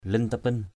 /lɪn-ta-pɪn/ (t.) huyền bí = mystérieux, insondable. mysterious, unfathomable.